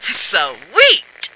flak_m/sounds/female2/int/F2sweet.ogg at efc08c3d1633b478afbfe5c214bbab017949b51b